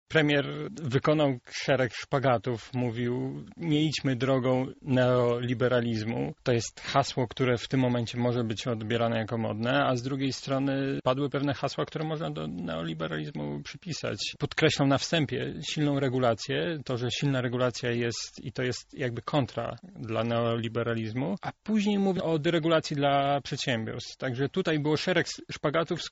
Zdaniem gościa Porannej Rozmowy Radia Centrum